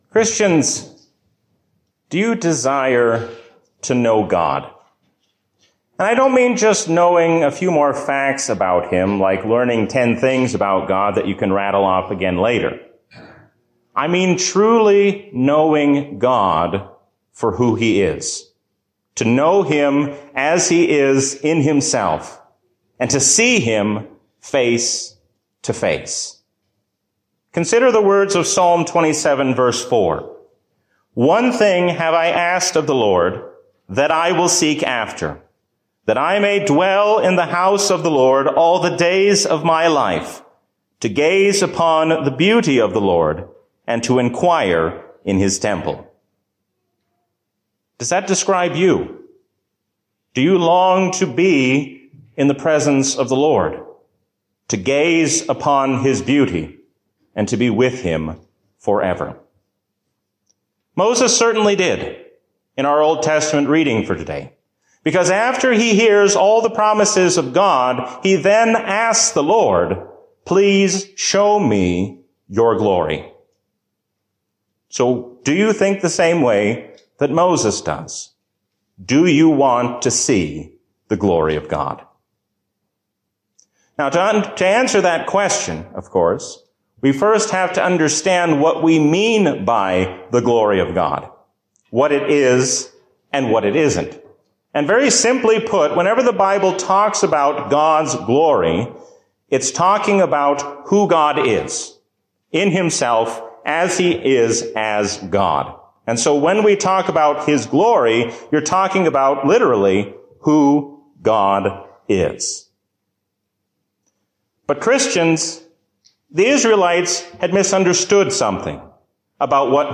A sermon from the season "Trinity 2024." The New Jerusalem shows us what it will be like to be with God in glory forever.